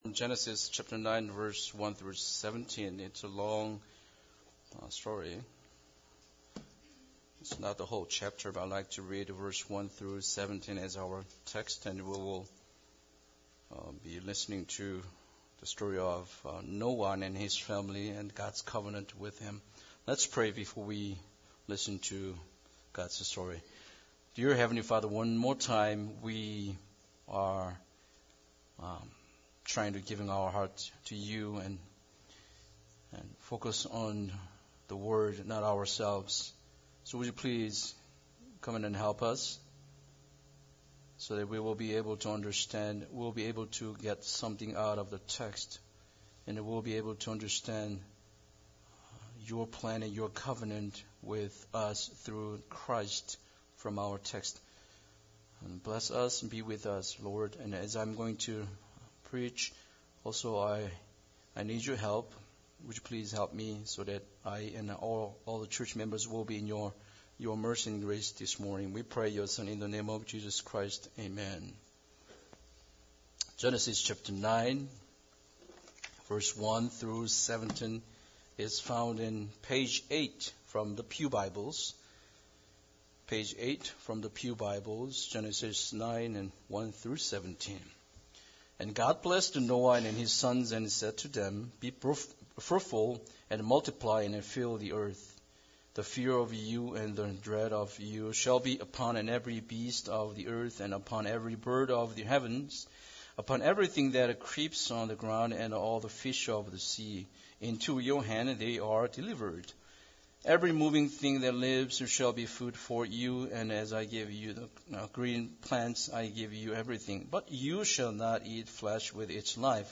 1-17 Service Type: Sunday Service Bible Text